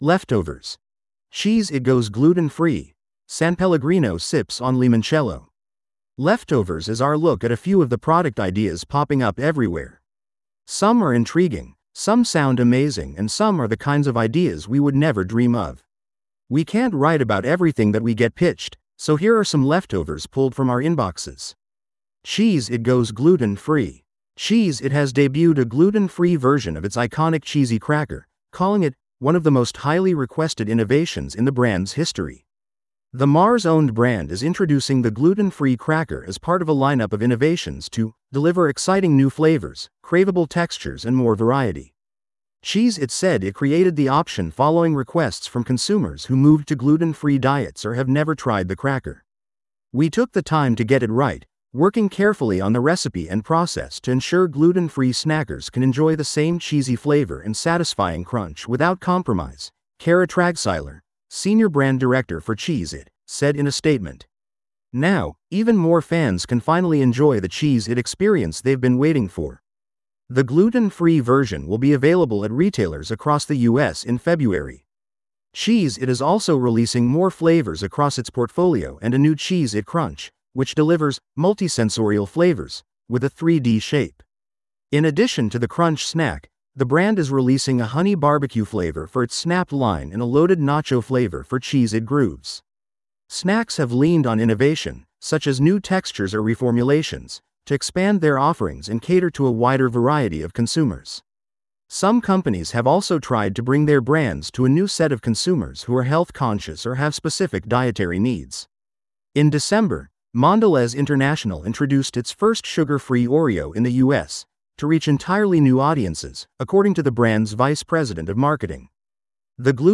This audio is generated automatically.